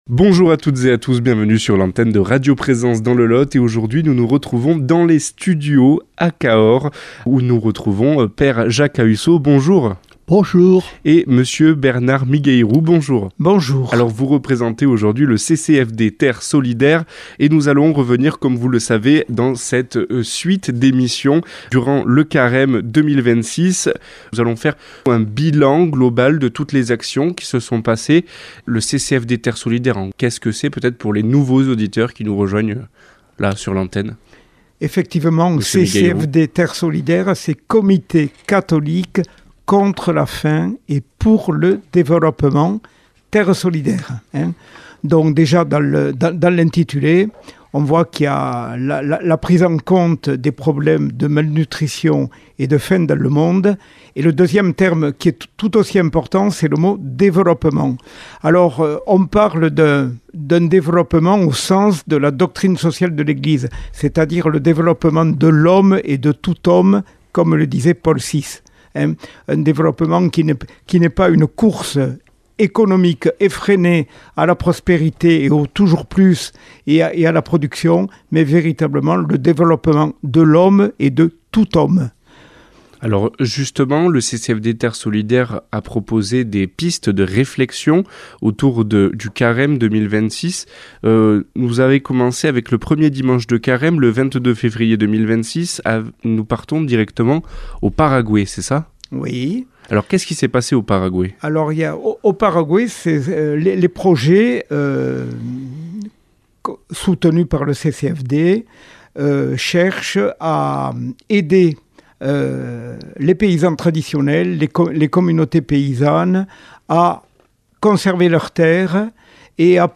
dans le studio de Cahors nous parle de la fin du Carême